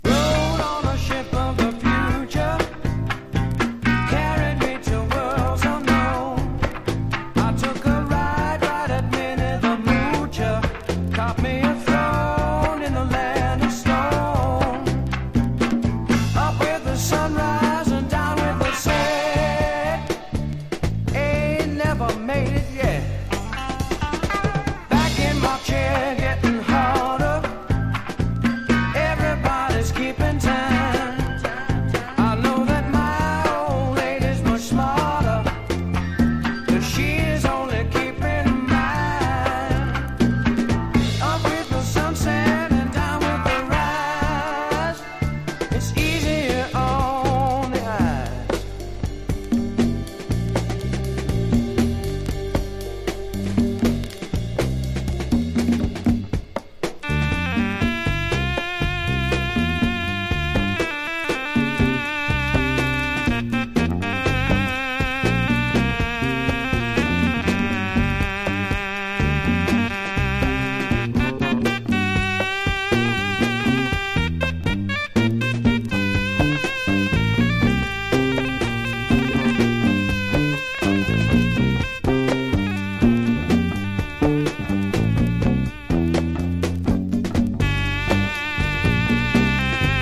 1. 70'S ROCK >
60年代的サイケ感覚をひきずったファンキー・スワンプ・ブルースロック！